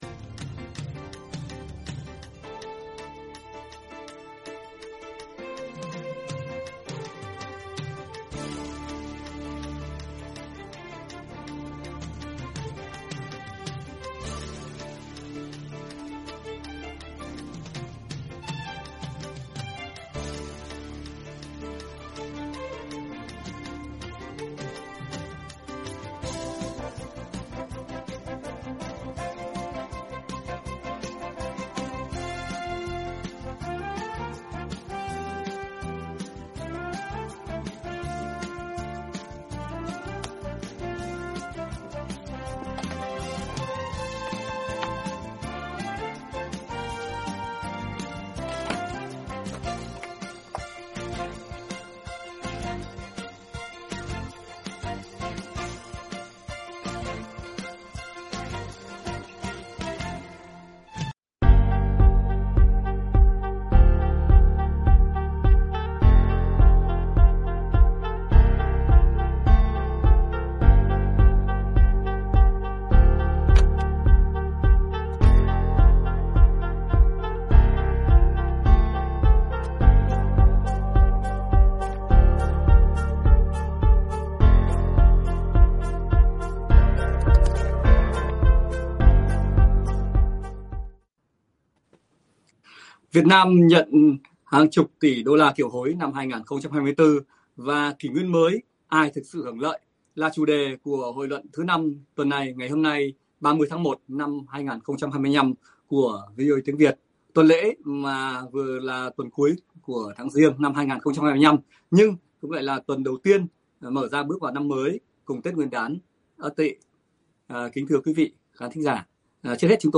Các khách mời là nhà văn, nhà báo, nhà quan sát thời sự, chính trị tham dự từ Hoa Kỳ, Việt Nam và hải ngoại cùng bình điểm, thảo luận một số diễn biến, sự kiện và vấn đề nổi bật, được quan tâm trong tuần lễ cuối của tháng Giêng 2025 và là tuần đầu bước vào năm mới & Tết nguyên đán Ất Tỵ.